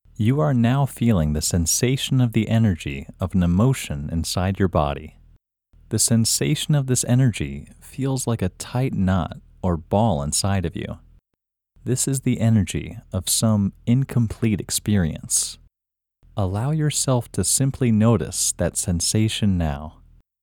IN – Second Way – English Male 2